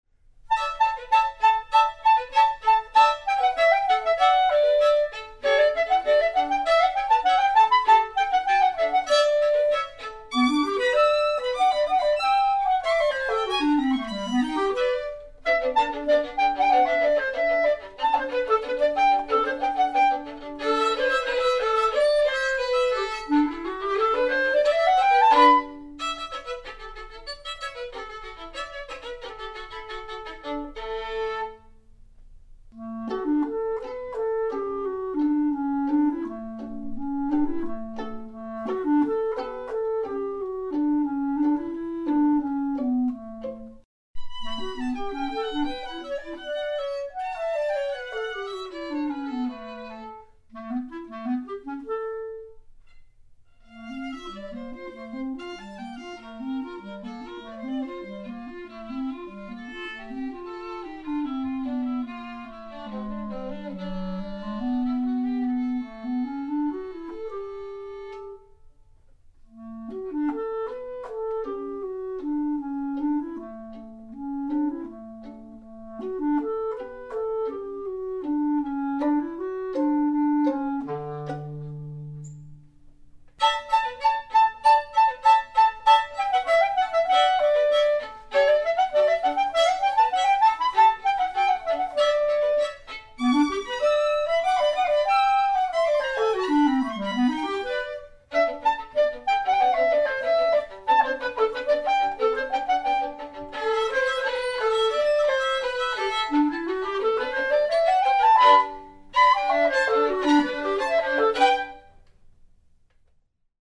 clarinet
Violin
Piano